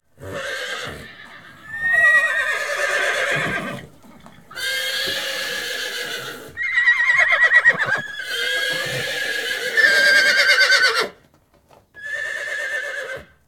horses.ogg